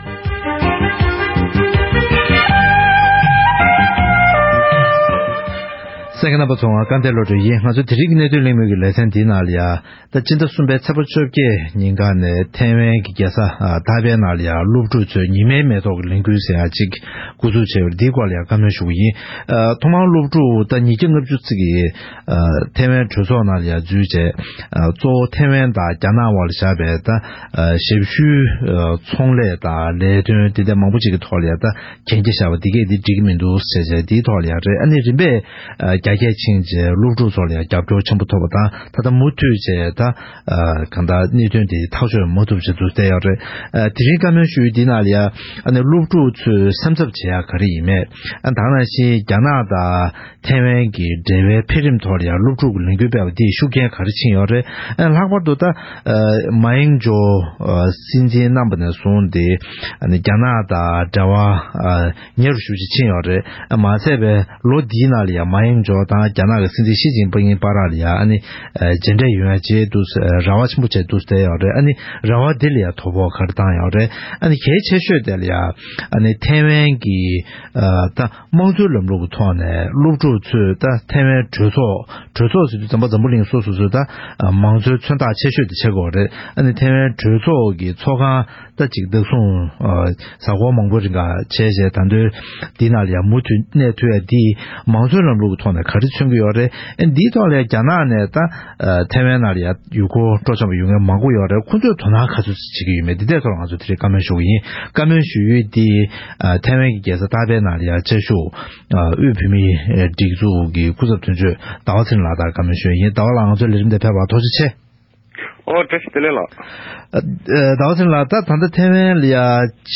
བཀའ་མོལ་ཞུས་པར་གསན་རོགས